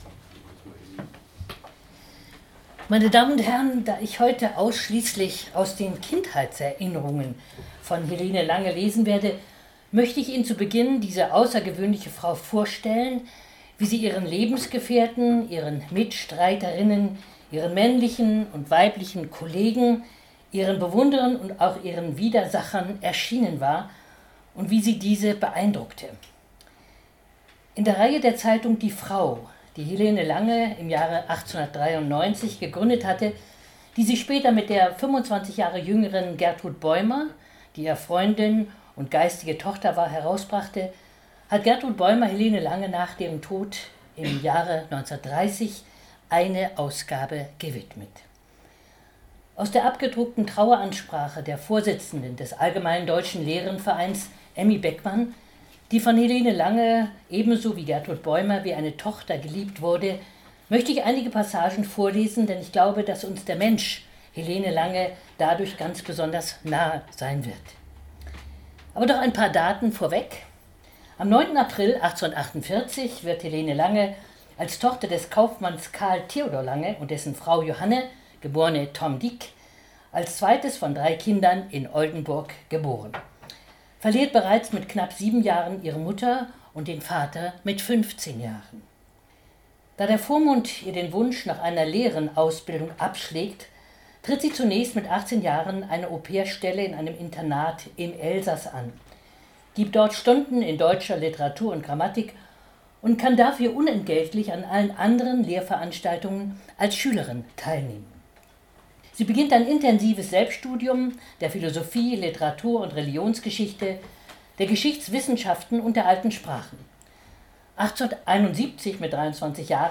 Die berühmteste Oldenburgerin, die Frauenrechtlerin Helene Lange, geboren im Zentrum der Stadt, erzählt in ihren Erinnerungen auf ausgesprochen vergnügliche Weise von ihrer Kinderzeit vor über 15o Jahren. Eine Lesung mit Bildern und Musik aus dem damaligen Oldenburg.
Einführung (PDF) Textbuch (PDF) Präsentation (PDF) Mitschnitt einer öffentlichen Veranstaltung (MP3, Audio) Ihr Browser unterstützt keine MP3-Dateien zurück zu den Programmen